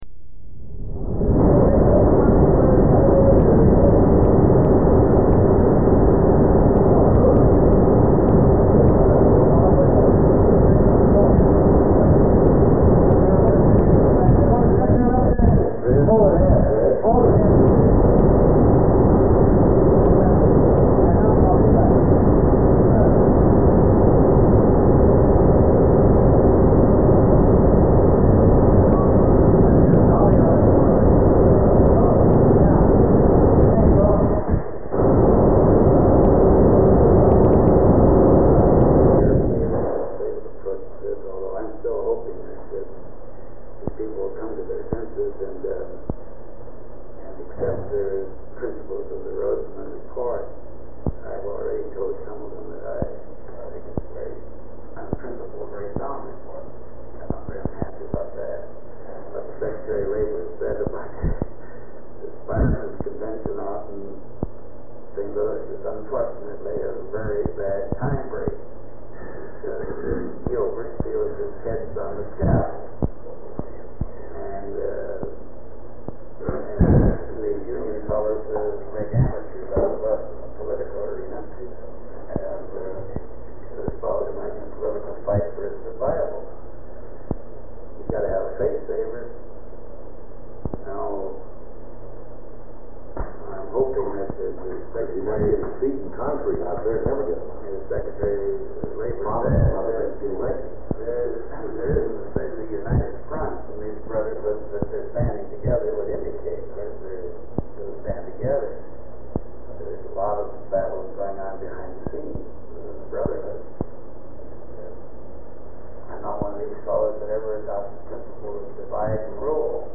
Sound recording of a meeting
Secret White House Tapes | John F. Kennedy Presidency Meetings: Tape 96.